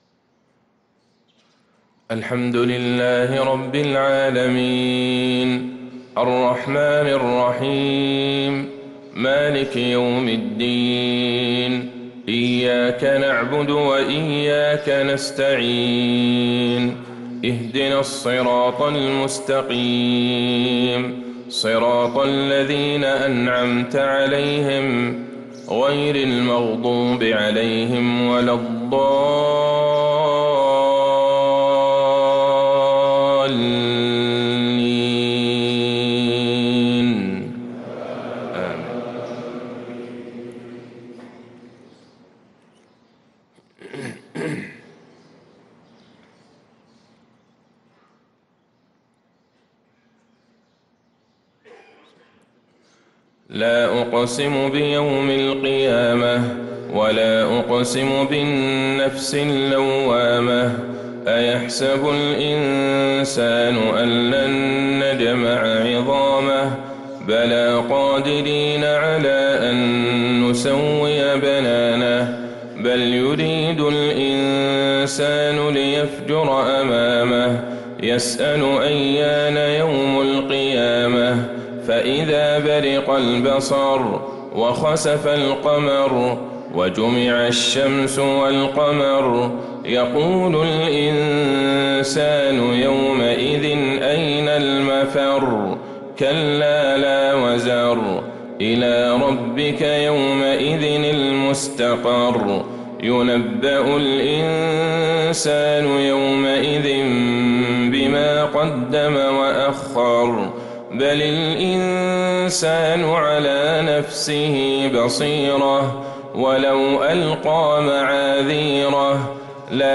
صلاة الفجر للقارئ عبدالله البعيجان 23 شعبان 1445 هـ
تِلَاوَات الْحَرَمَيْن .